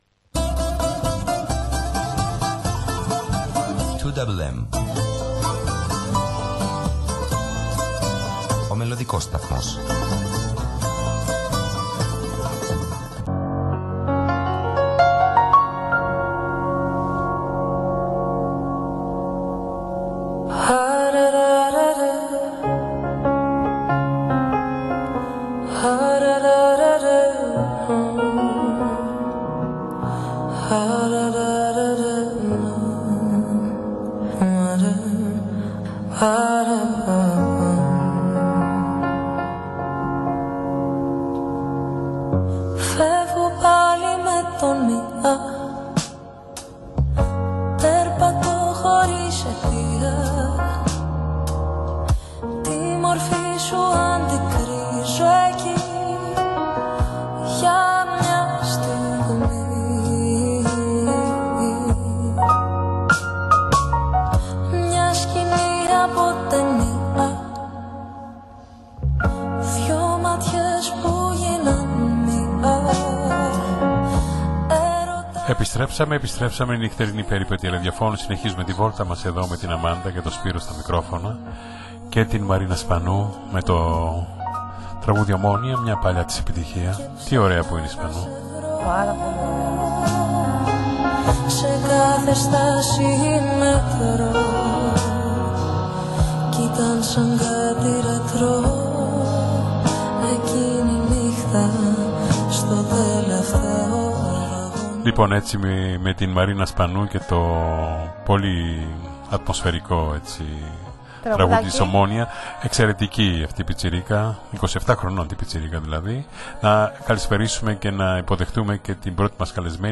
Σε μία ζωντανή συνέντευξη